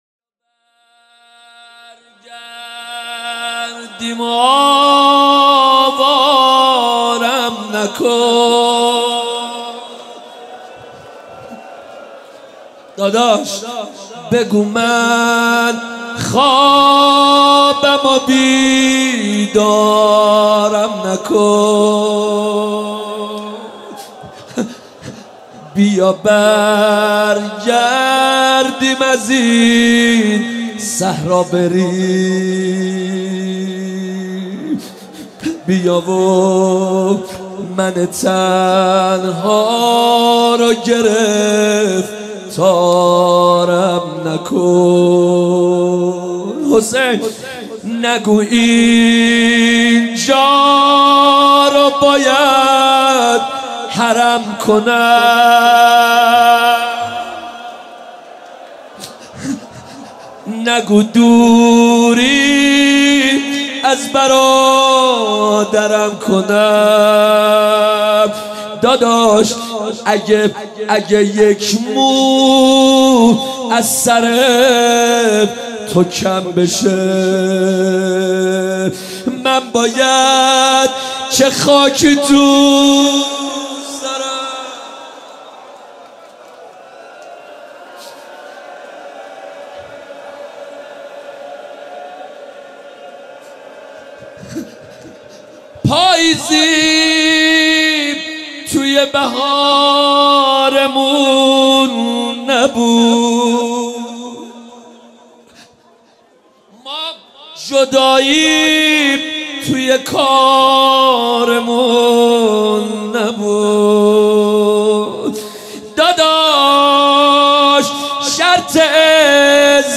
هیات مکتب الحسین اصفهان
بیا برگردیم و  و آوارم نکن | روضه | ورود کاروان امام حسین به کربلا